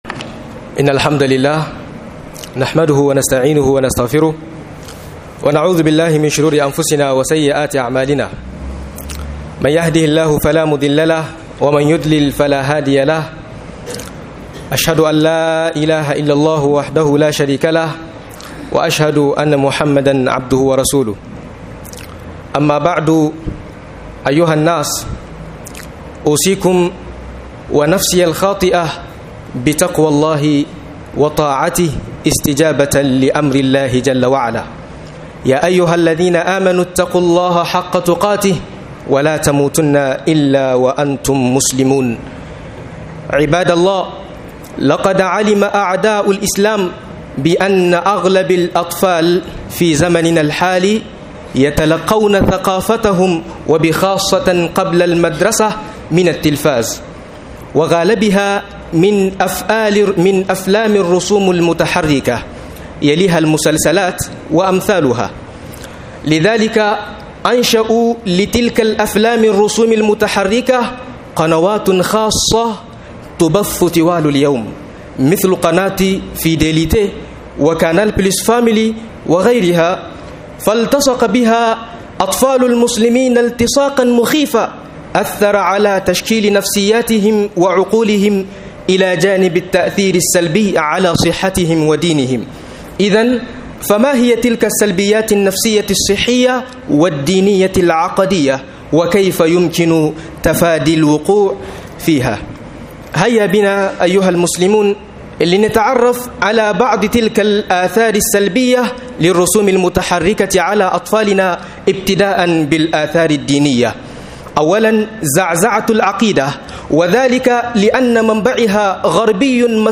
Tasirin design animé ga Tarbiyyar yara - MUHADARA